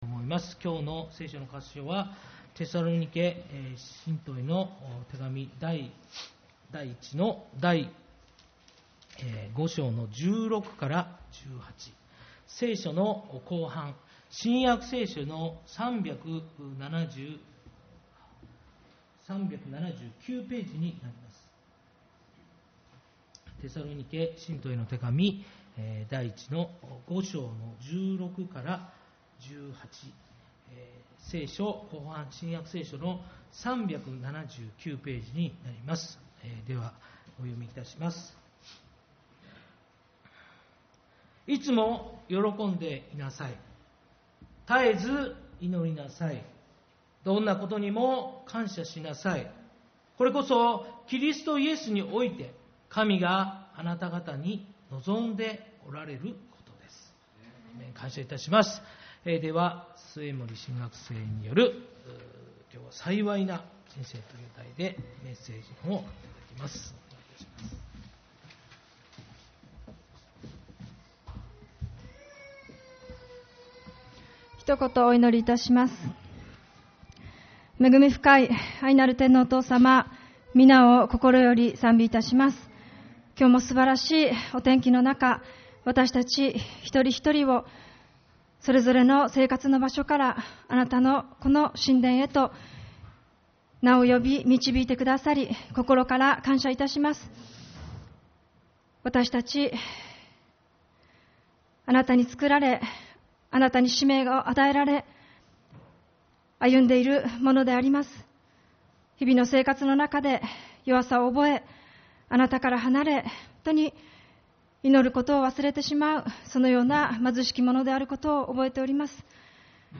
7月28日主日礼拝 「幸いな人生」